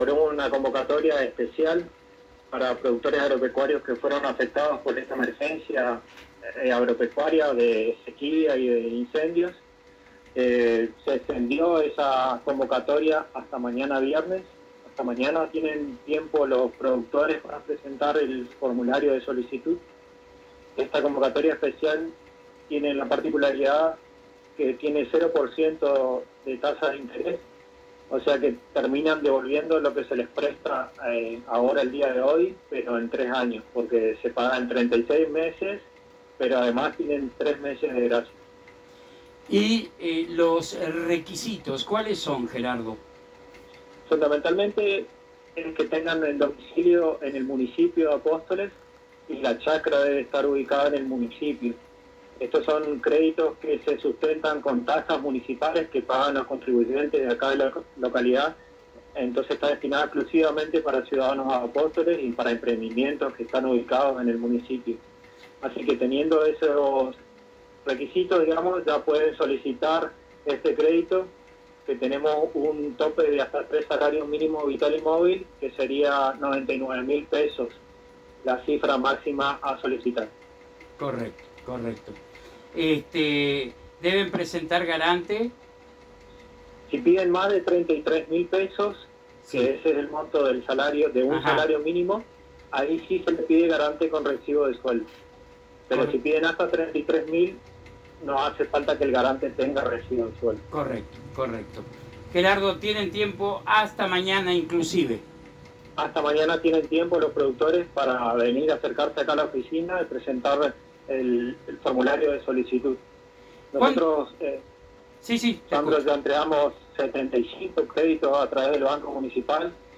Gerardo Cura, Director del Banco Social Municipal, en diálogo con la ANG y FM Éxito manifestó que el plazo para la presentación de pedido de crédito para pequeños Productores afectados por la sequía e incendios vence el viernes 18 de marzo.